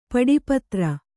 ♪ paḍi patra